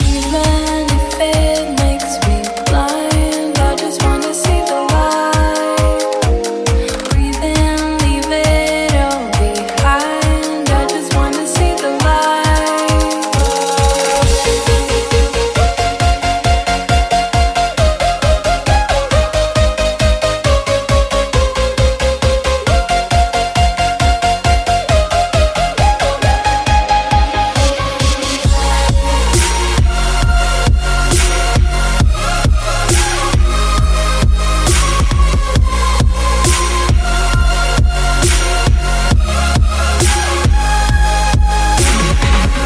Genere: pop, club, deep, remix